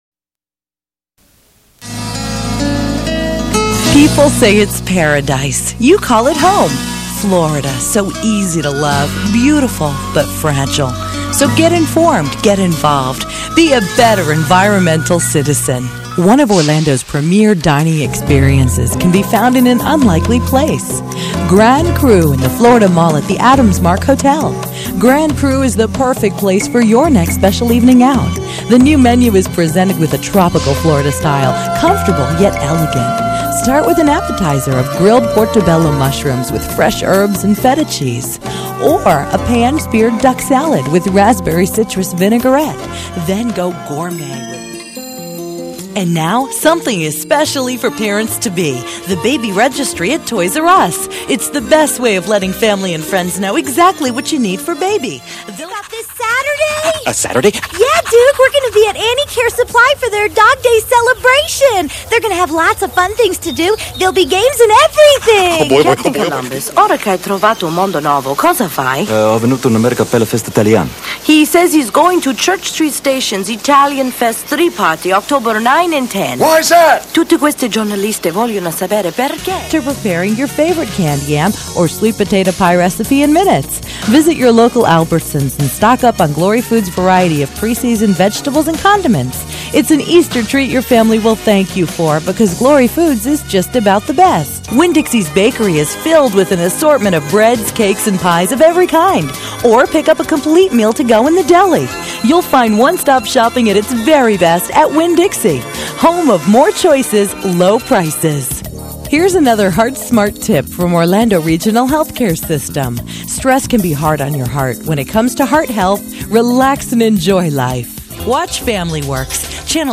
Voice Over Demos
Voice Over